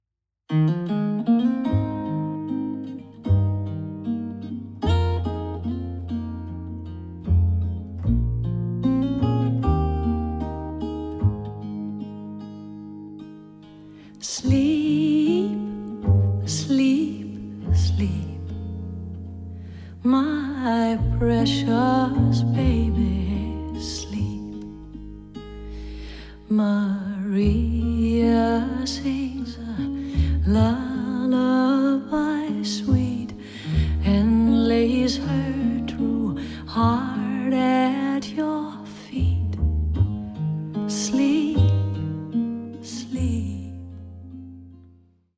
録音：2014年 ミュンヘン